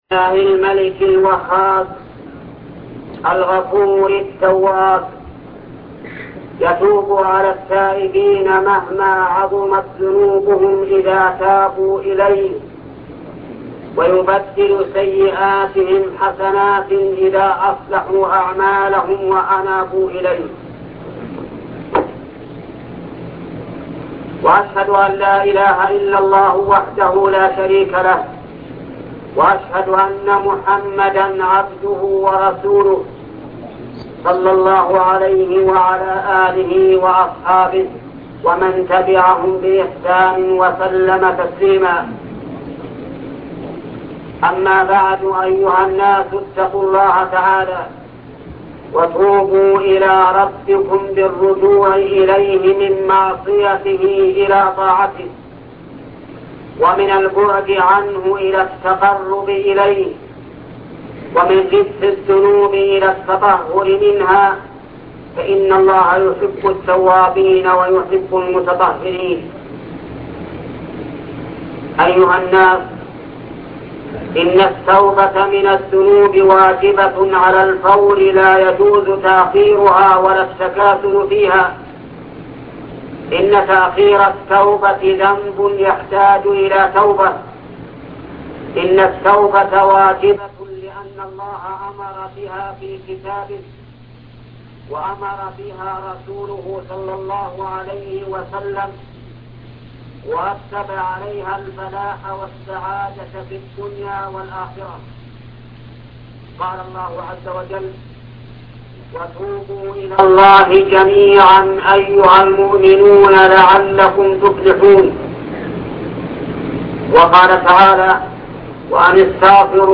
خطبة التوبة الشيخ محمد بن صالح العثيمين